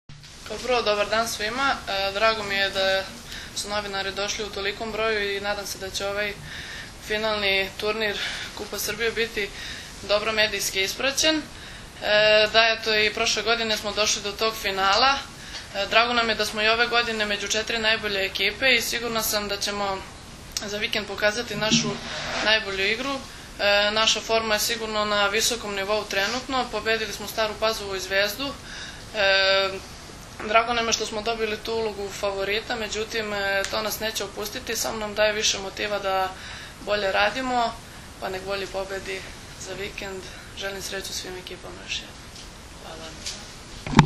U prostorijama Odbojkaškog saveza Srbije danas je održana konferencija za novinare povodom Finalnog turnira 48. Kupa Srbije u konkurenciji odbojkašica, koji će se u subotu i nedelju odigrati u dvorani “Park” u Staroj Pazovi.